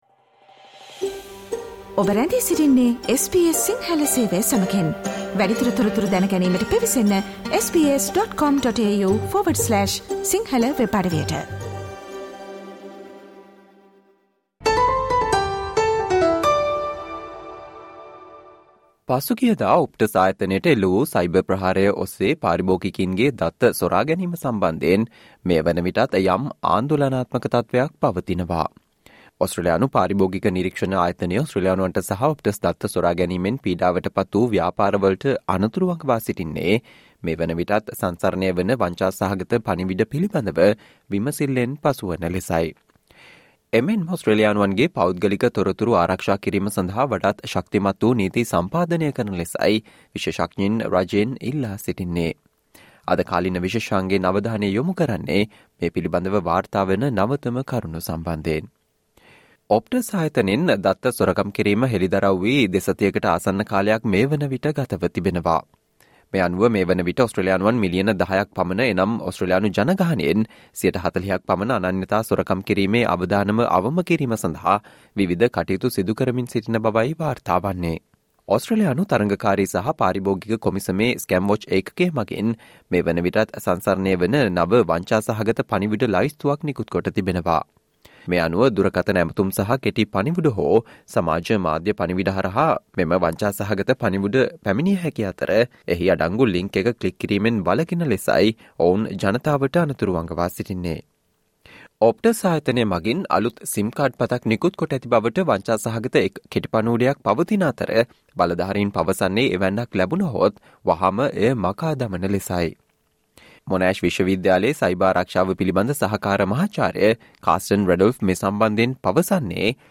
Today - 04 October, SBS Sinhala Radio current Affair Feature on "Scamwatch" warns Australians to be vigilant against scams following Optus breach